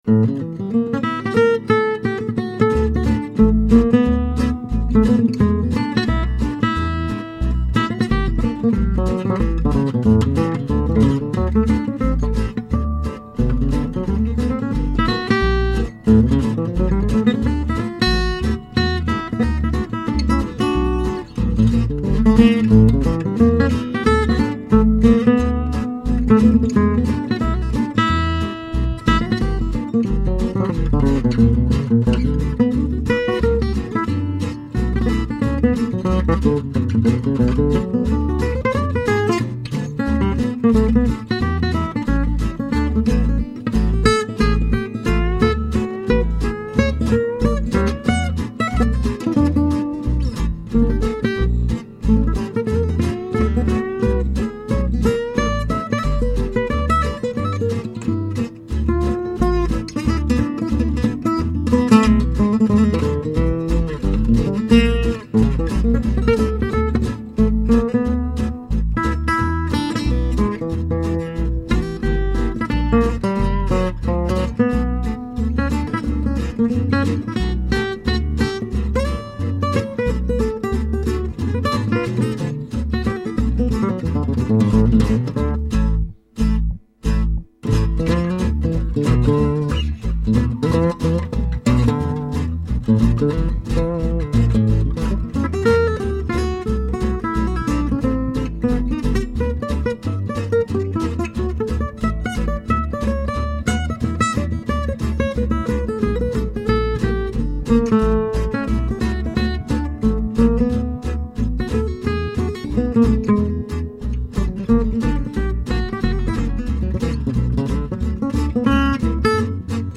Guitare Jazz Manouche • Voir le sujet - Mademoiselle Lulu Lee
Sinon, pour le tempo, 90 à la blanche, c'est comme ça que je l'aime, donc les amphétaminés de frais et autres fous du copeau, camenbert!
:o Super utilisation musicale des levers de cordes, beau thème, belle grille derrière et beaux arrangements!
on sent que tu te fais plaisir ya une bonne ambiance dans cette compo
On se croirait au bord de la plage...c'est cool et ça coule tout seul.
C'est mélodieux, ça swingue...